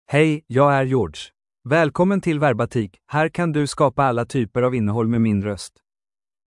GeorgeMale Swedish AI voice
Voice sample
Listen to George's male Swedish voice.
Male
George delivers clear pronunciation with authentic Sweden Swedish intonation, making your content sound professionally produced.